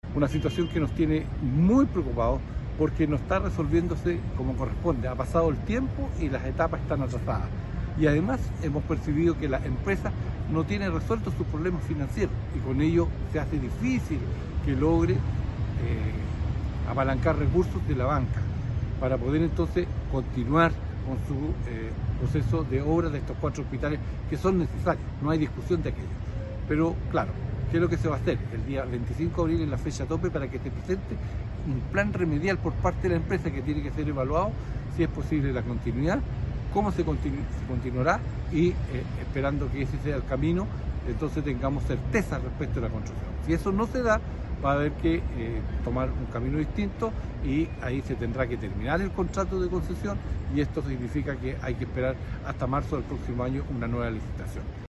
El senador Saavedra explicó que “la empresa no ha resuelto sus problemas financieros, lo que dificulta su capacidad para continuar con las obras”.